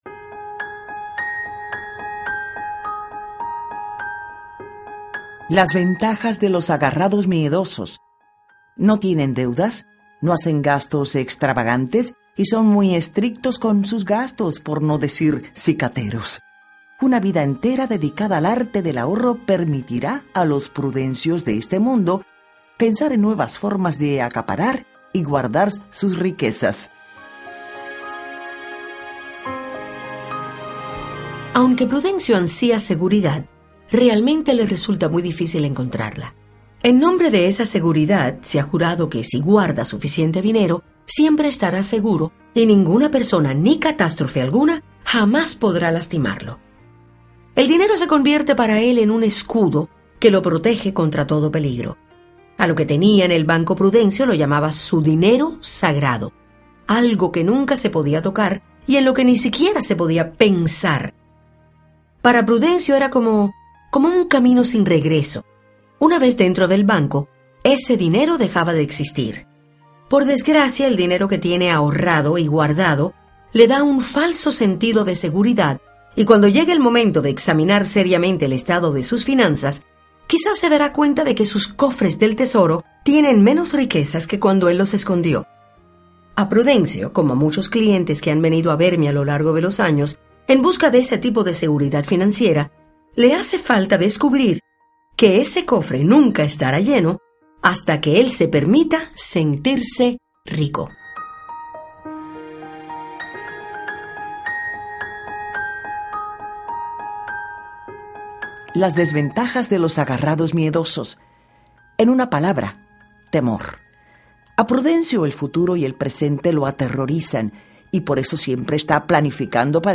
Audio Libros